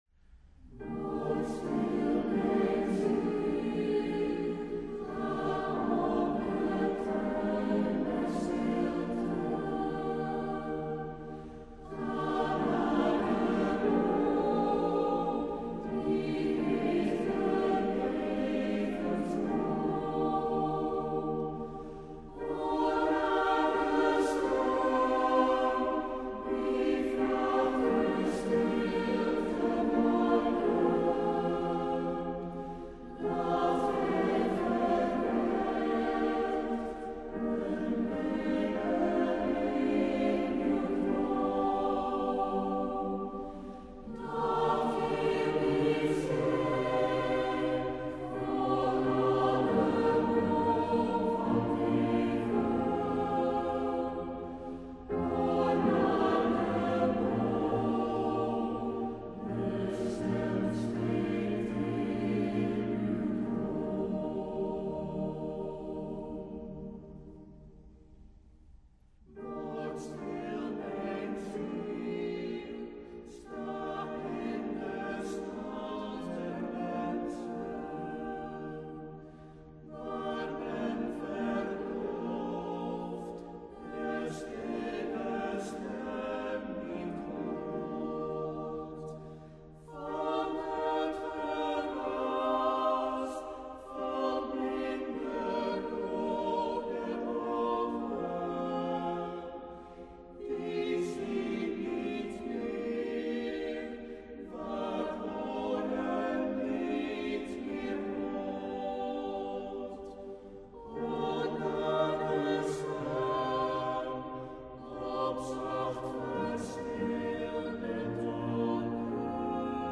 op anglicaanse melodieën